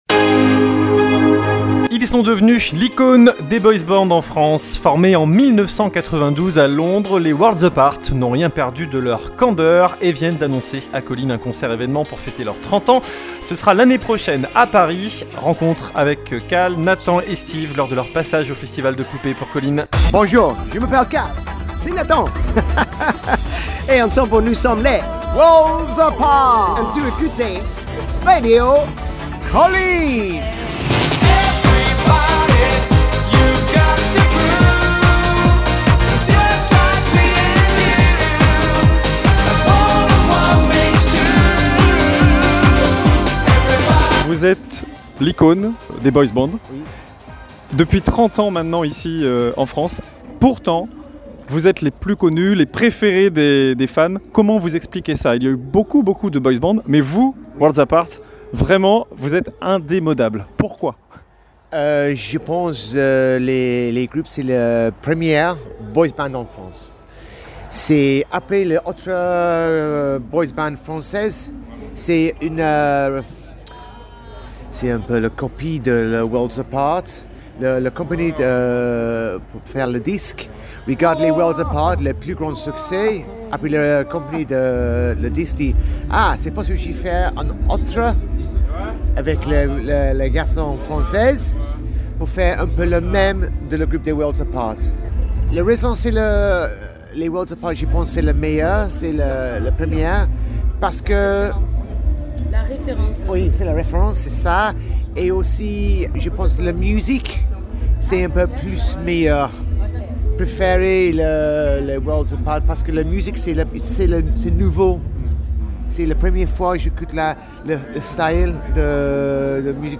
Les Worlds Apart en interview